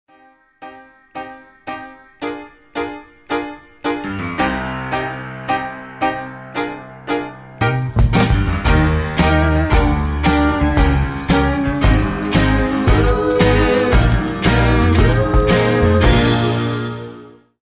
175 - SOUL